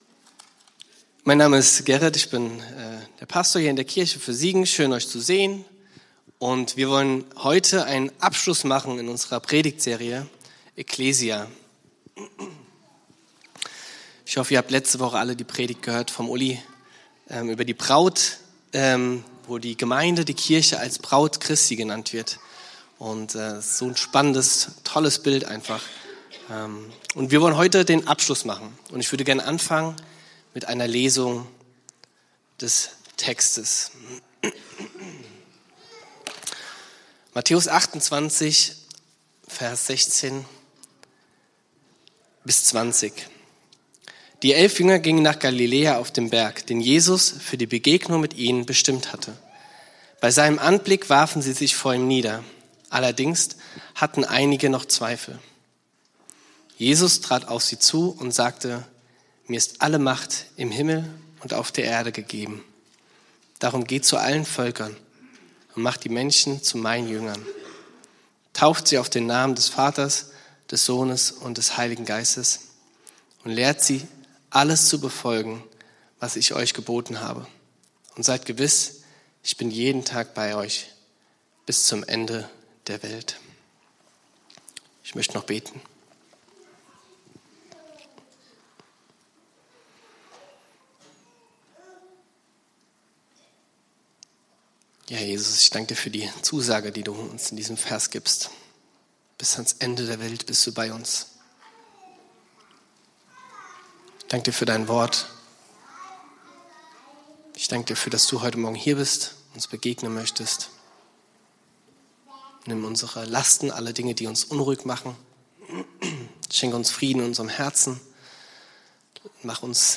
Predigt vom 29.03.2026 in der Kirche für Siegen